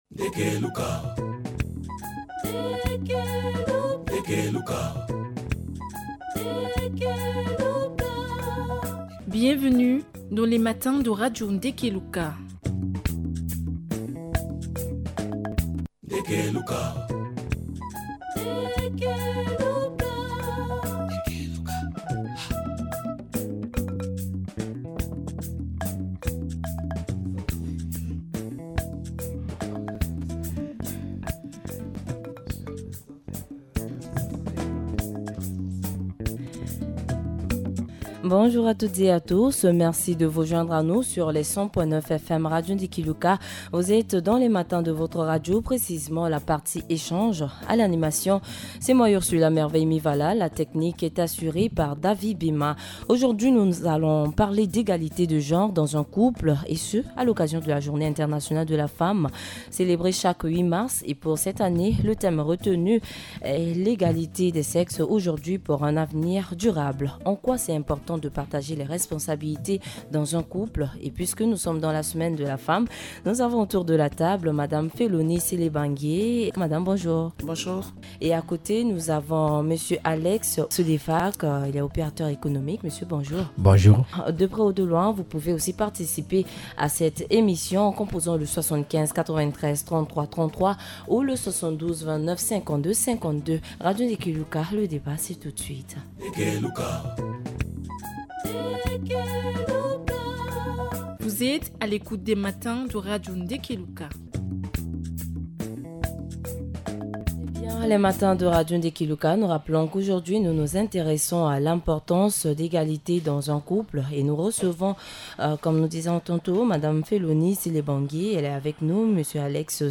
En cette semaine de la femme, Table Ronde des Matins de Ndeke Luka se penche sur : L’égalité de genre dans un couple. En quoi est-il important de partager les responsabilités dans un couple ?